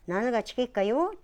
Aizu Dialect Database
Type: Yes/no question
Final intonation: Rising
Location: Aizumisatomachi/会津美里町
Sex: Female